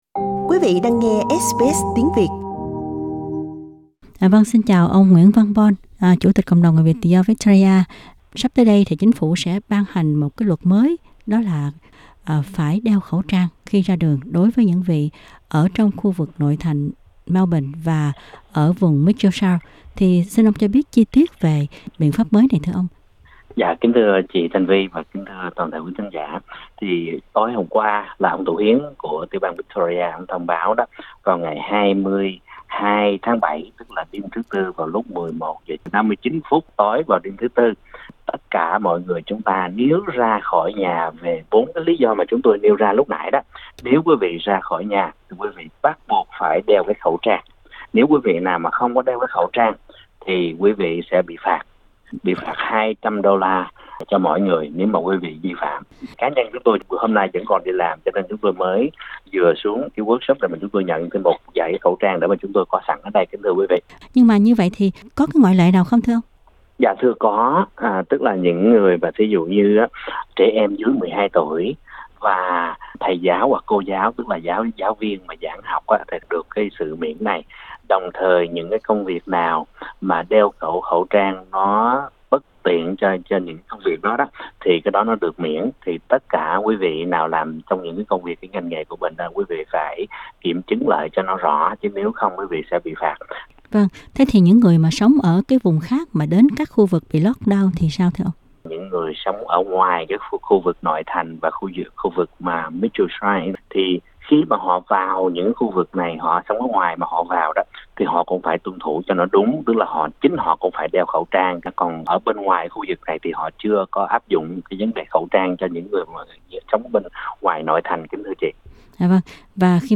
trong phần phỏng vấn đầu trang trình bày những vấn đề sau đây: